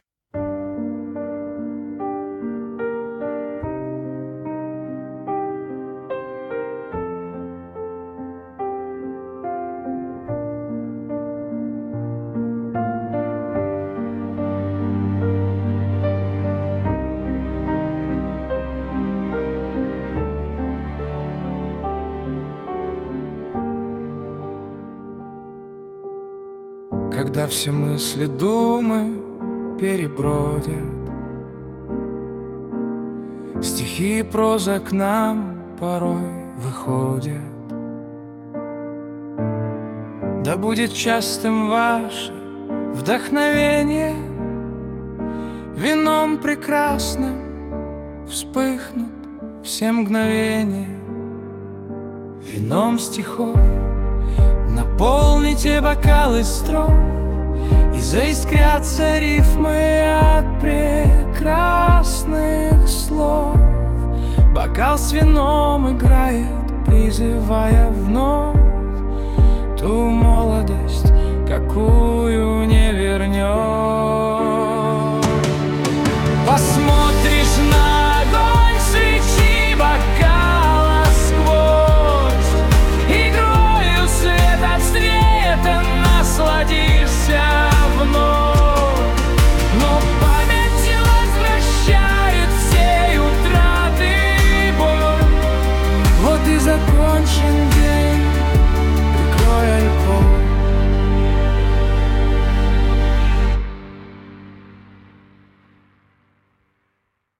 • Статья: Лирика
Романс